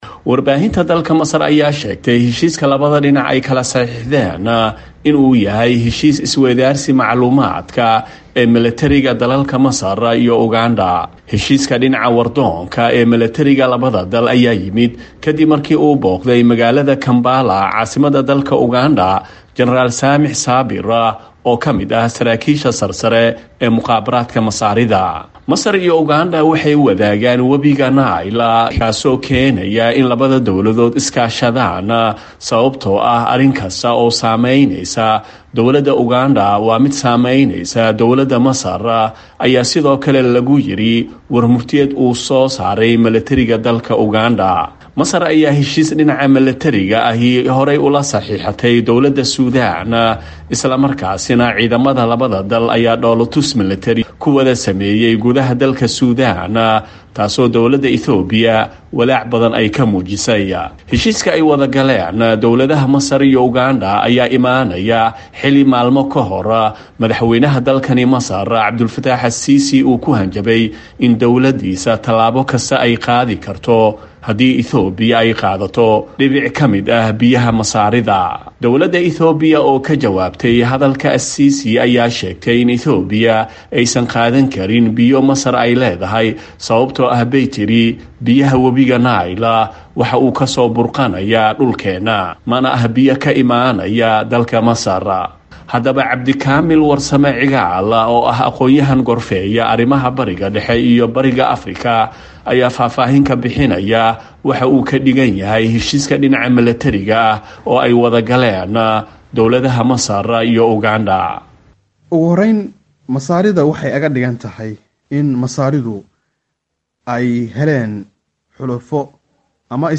Warbixin arrintan ku saabsan waxaa inoo soo direy wariyaheena magaalada Qahira